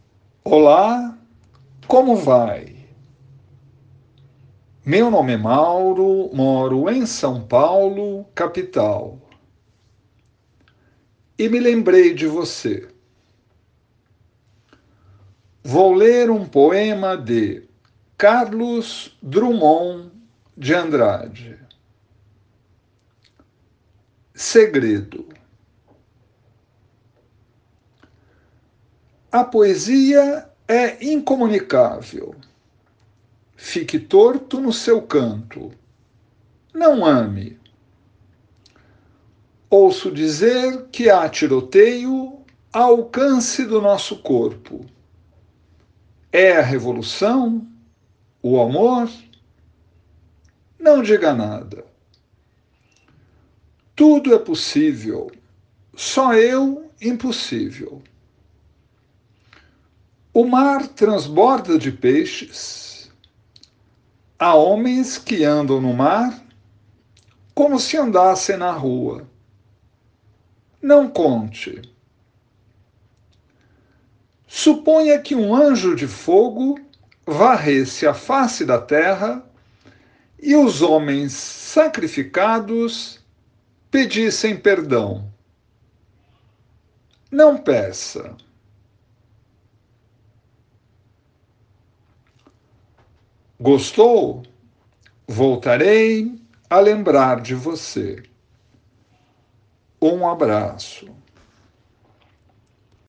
Poesia Português